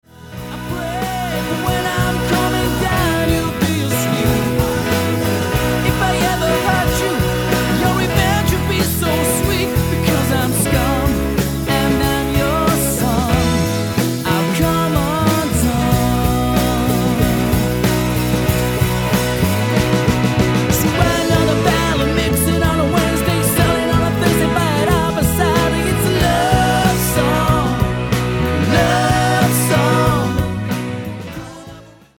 • Can perform solo to backing tracks or with full live band